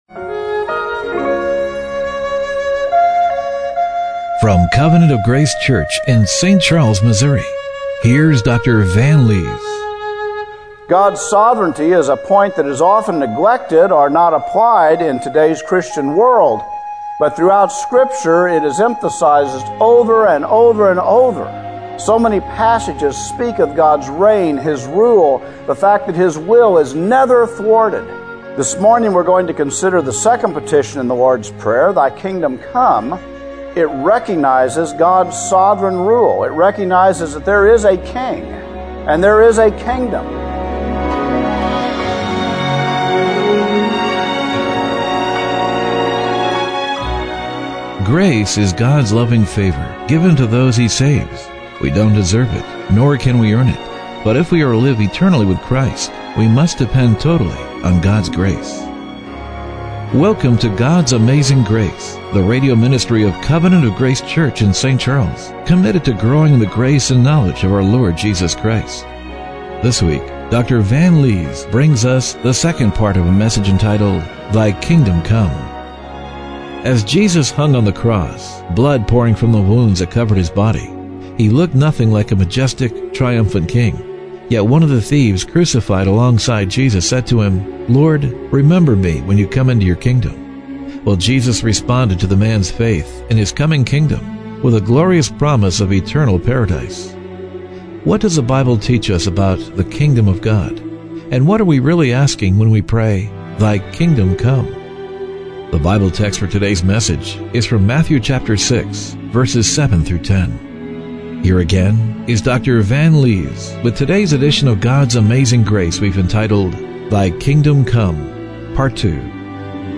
Matthew 6:7-10 Service Type: Radio Broadcast What does the Bible teach us about the Kingdom of God?